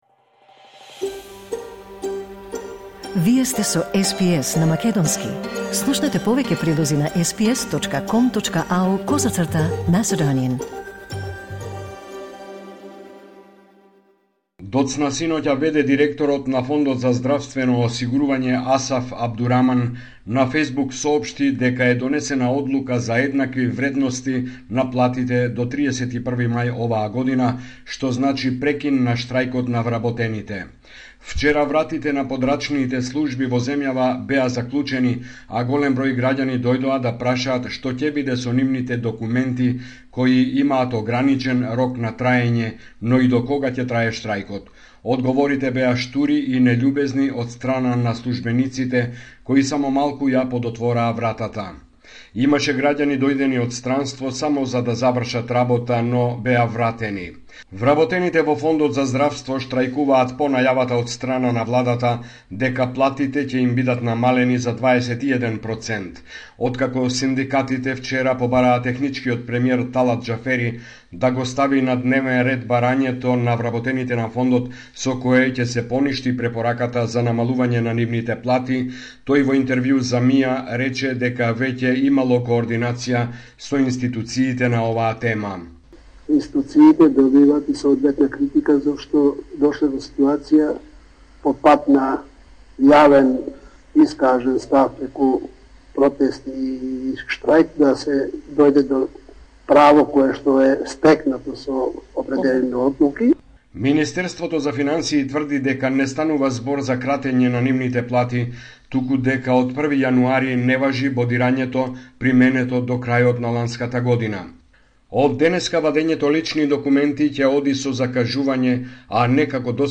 Homeland Report in Macedonian 7 February 2024